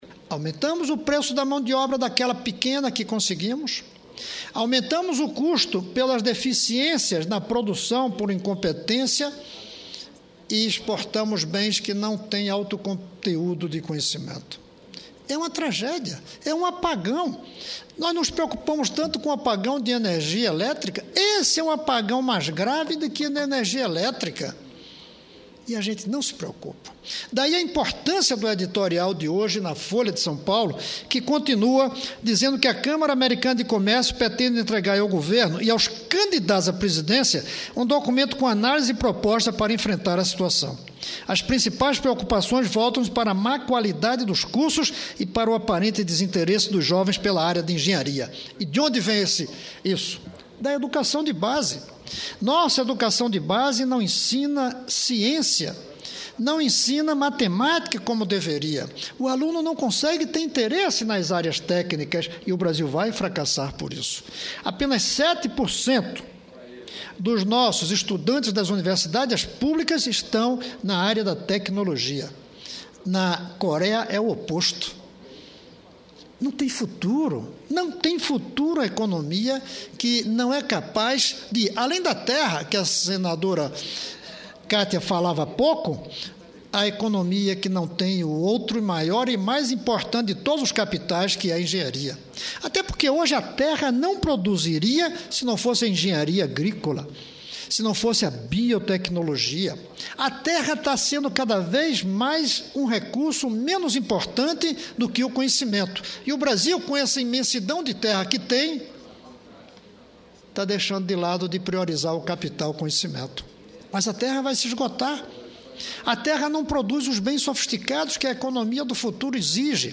Cristovam Buarque (PDT-DF) lê matéria sobre falta de engenheiros no país - 2ª parte